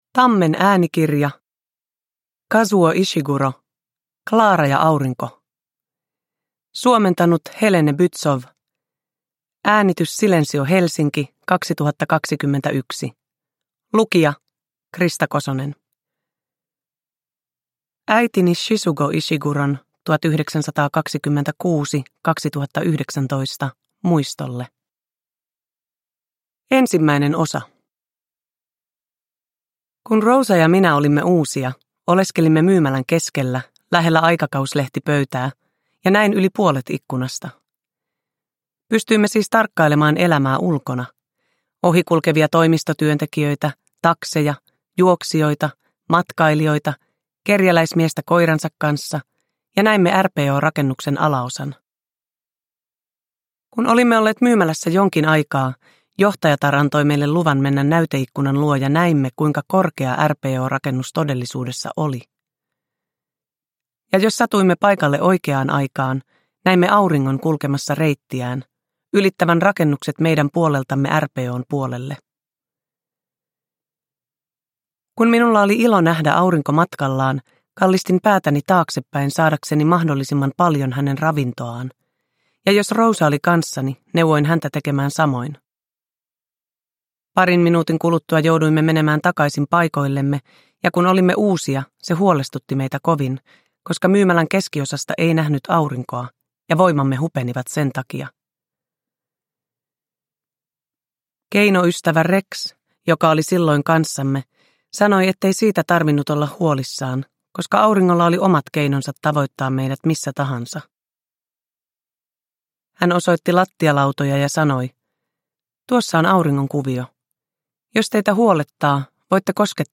Klara ja aurinko – Ljudbok – Laddas ner
Uppläsare: Krista Kosonen